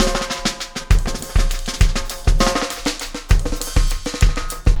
Extra Terrestrial Beat 10.wav